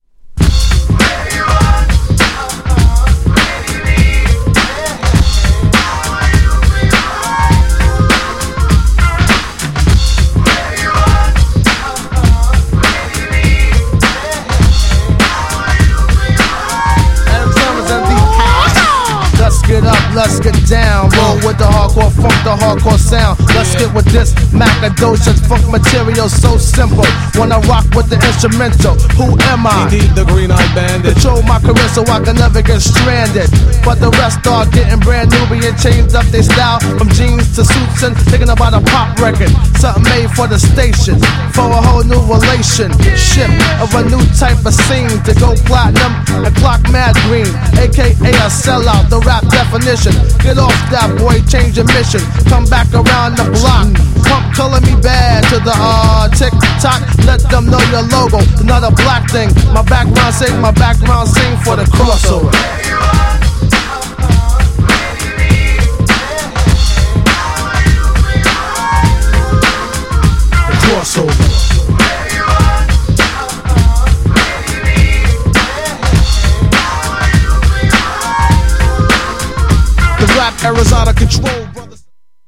GENRE Hip Hop
BPM 131〜135BPM